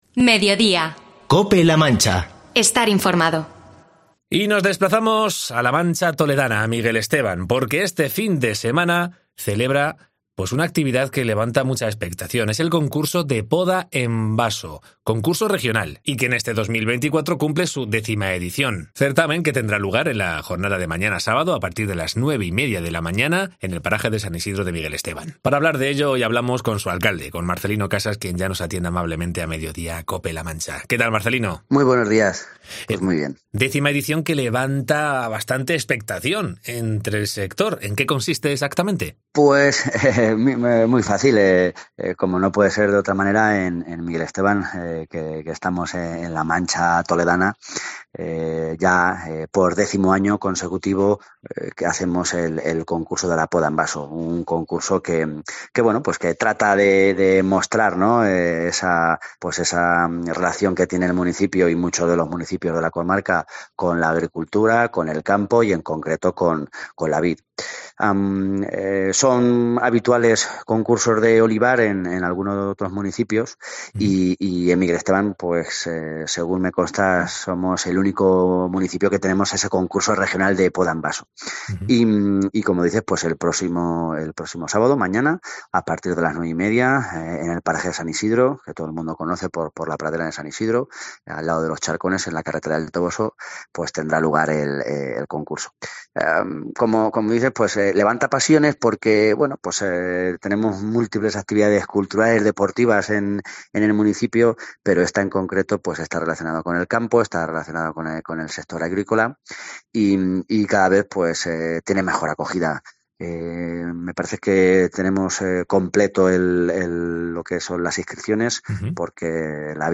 Entrevista con Marcelino Casas, alcalde de Miguel Esteban con motivo del X Concurso Regional de Poda en Vaso